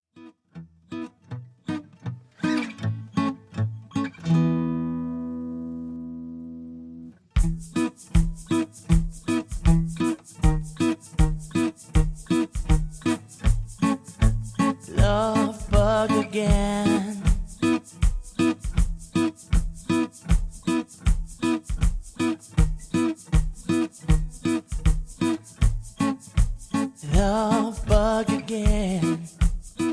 (Version-2, Key-E) Karaoke MP3 Backing Tracks
Just Plain & Simply "GREAT MUSIC" (No Lyrics).
karaoke , mp3 backing tracks